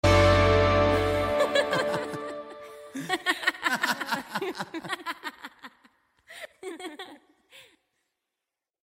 Risada Irmãos DT